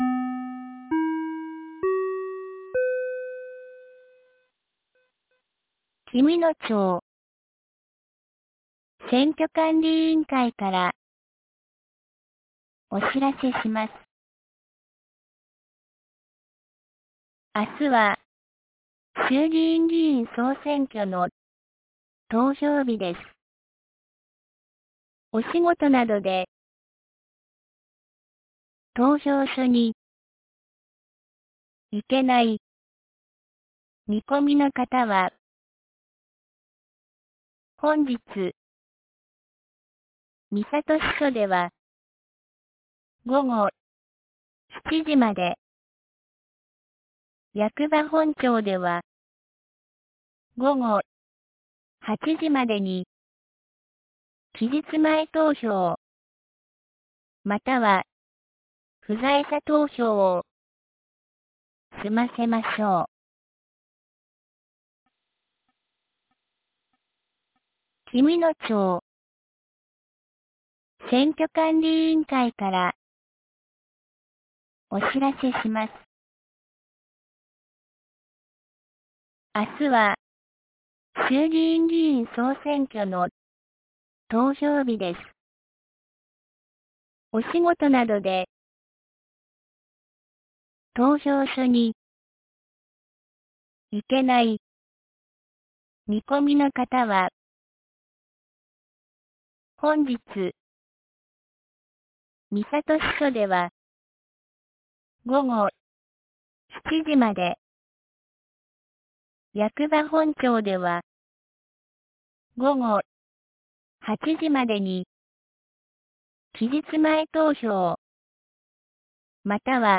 2024年10月26日 17時12分に、紀美野町より全地区へ放送がありました。